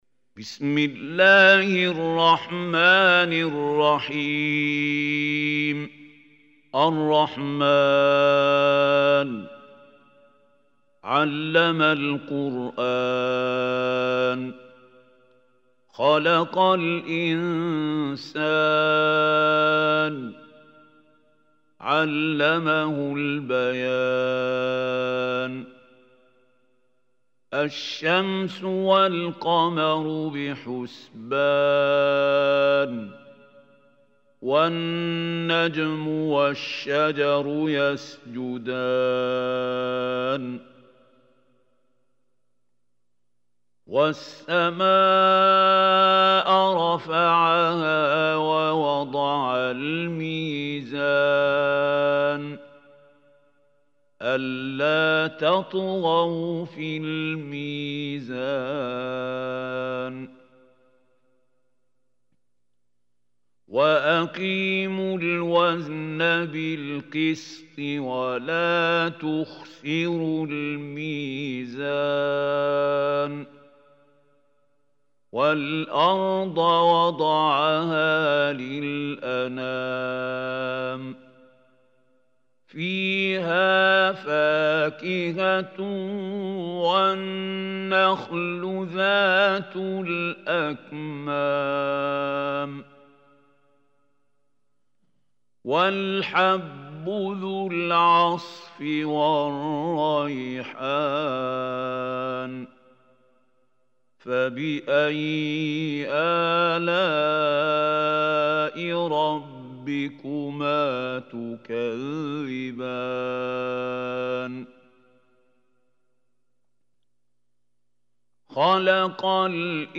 Surah Rahman Recitation MP3 Mahmoud Khalil Hussary
Surah Rahman is 55 surah of Holy Quran. Listen or play online mp3 tilawat / recitation in Arabic in the beautiful voice of Sheikh Mahmoud AL Hussary.